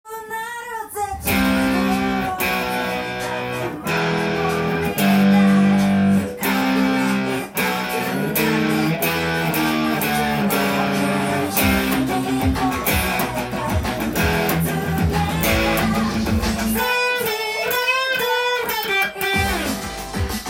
音源に合わせて譜面通り弾いてみました
シンコペーションと言われる裏のリズムが沢山使われています。
表と裏が交互に入ってきます。